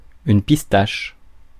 Ääntäminen
Ääntäminen France: IPA: [pis.taʃ] Haettu sana löytyi näillä lähdekielillä: ranska Käännös Substantiivit 1. pistachio Adjektiivit 2. pistachio Suku: m .